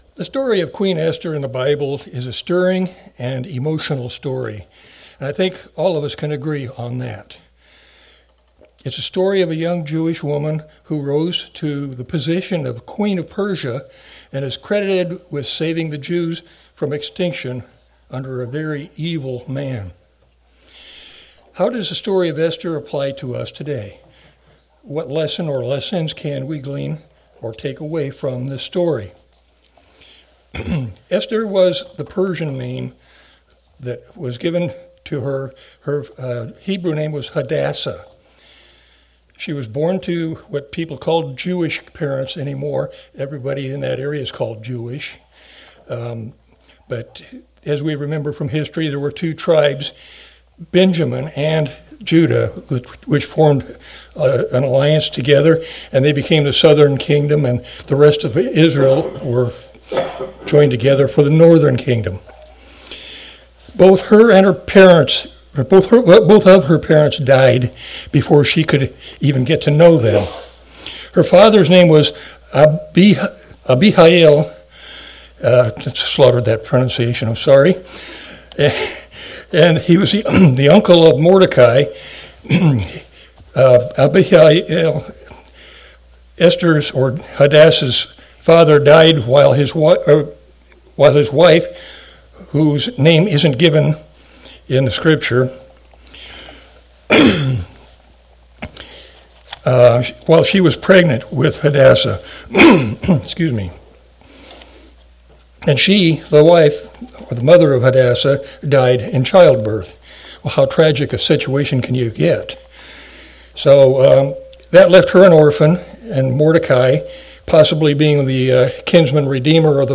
Sermons
Given in Omaha, NE